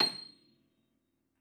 53h-pno26-C6.wav